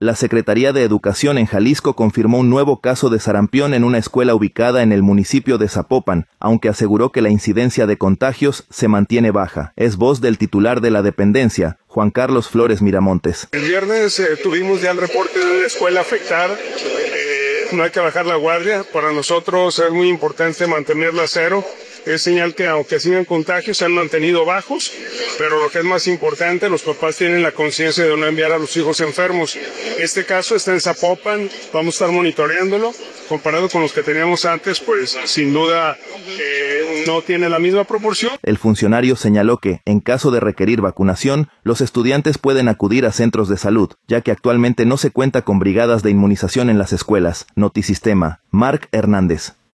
La Secretaría de Educación en Jalisco (SEJ) confirmó un nuevo caso de sarampión en una escuela ubicada en el municipio de Zapopan, aunque aseguró que la incidencia de contagios se mantiene baja. Es voz del titular de la dependencia, Juan Carlos Flores Miramontes.